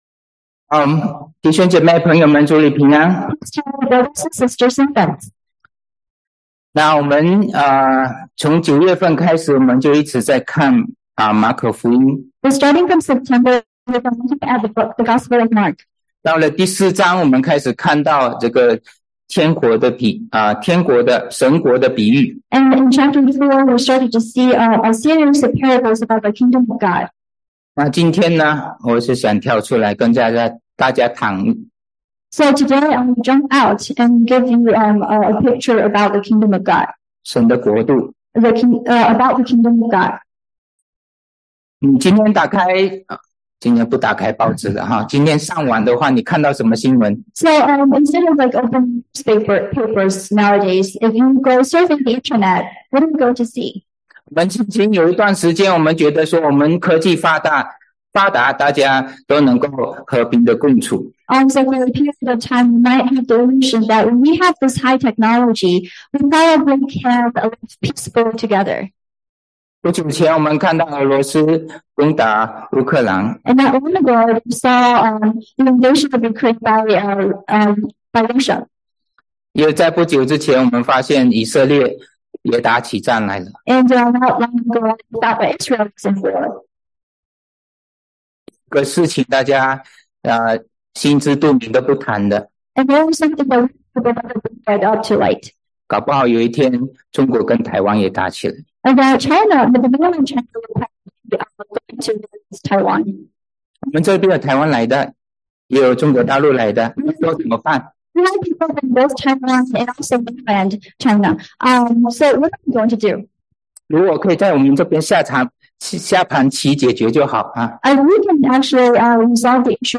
英文講道 Home / 英文講道